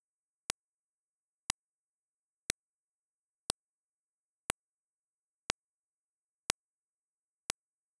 Was als 8 vertikale dünne Linien erscheint ist 16 mal das selbe, ein-Sample-kurze, Klicksignal. Zuerst auf beiden Spuren gleichzeitig, dann mit einer Millisekunde Abstand, dann folgen immer kleinere Abstände von einer halben Millisekunde, einer Viertel, einer Achtel, einer Sechzehntel Millisekunde bis zum Unterschied von einem Sample Differenz = 0,00227ms.
1ms-bis-1sample.wav